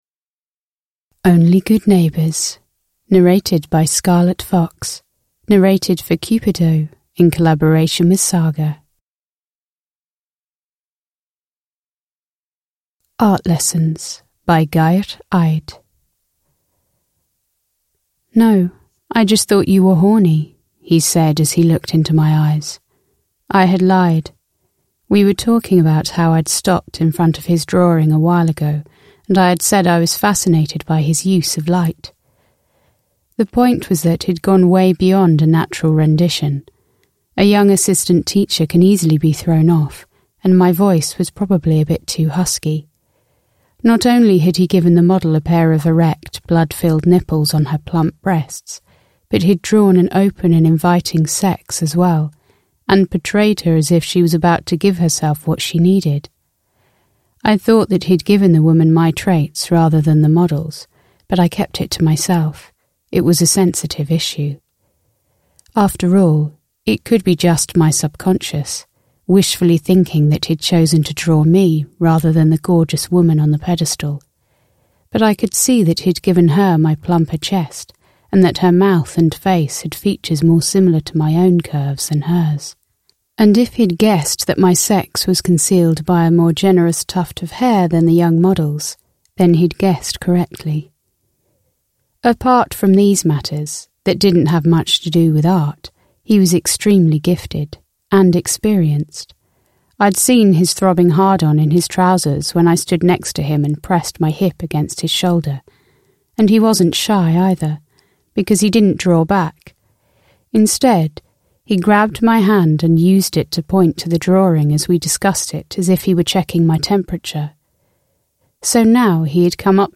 Only good neighbours (ljudbok) av Cupido